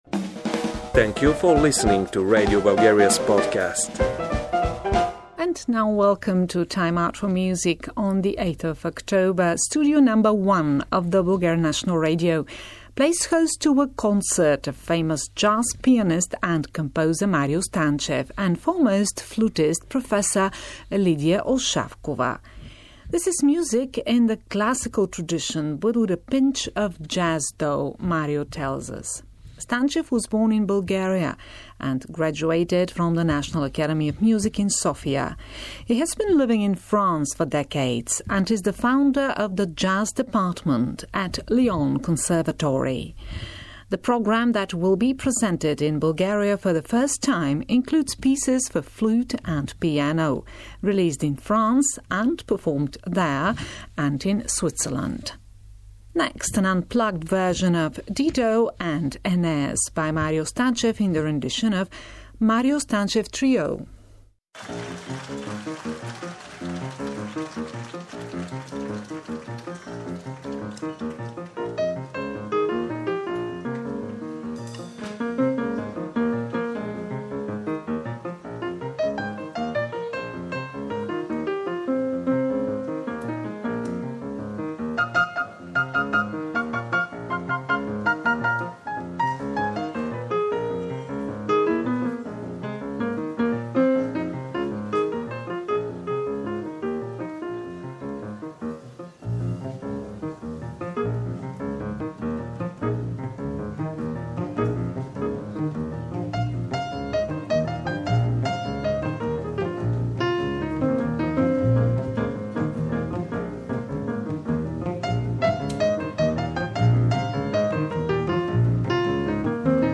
classical music with a pinch of jazz